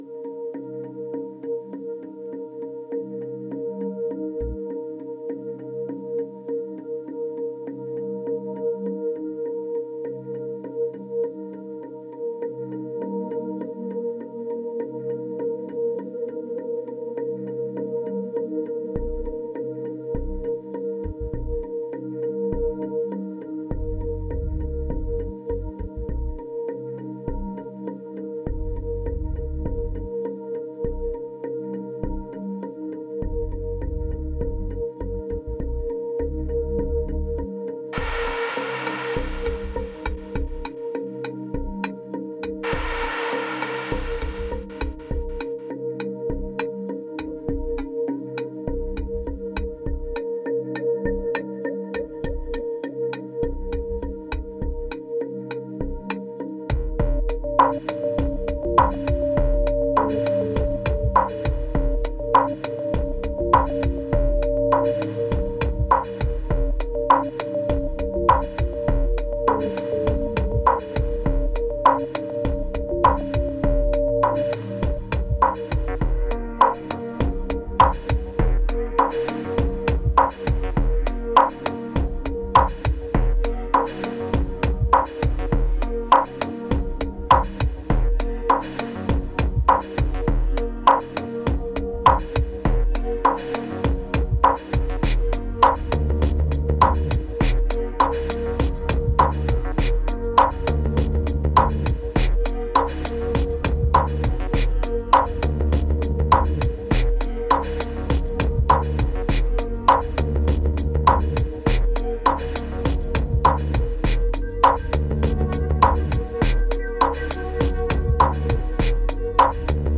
2707📈 - 82%🤔 - 101BPM🔊 - 2016-08-19📅 - 784🌟